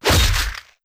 Melee Weapon Attack 31.wav